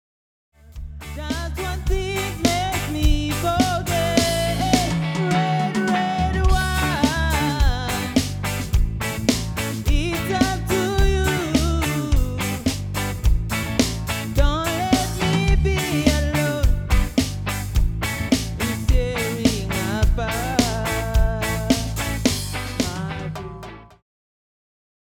Demosounds
Cover
Gesang
Gitarre
Bass
Schlagzeug